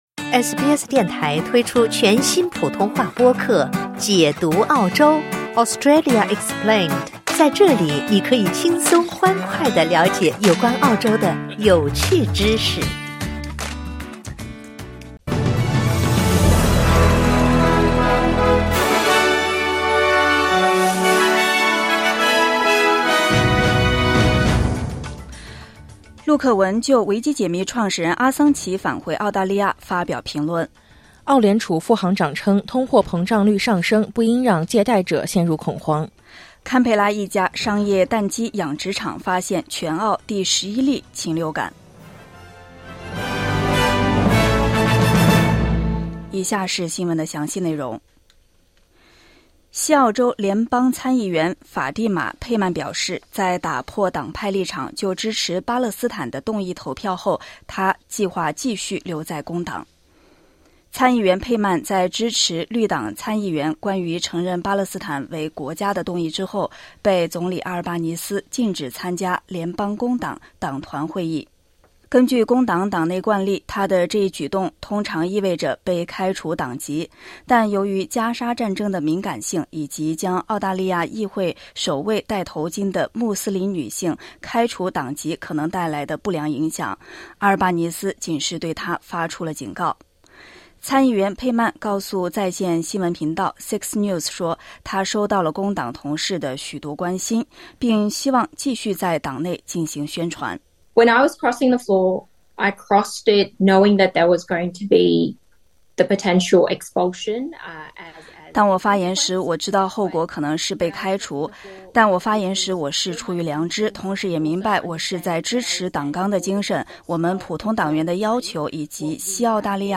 SBS早新闻（2024年6月28日）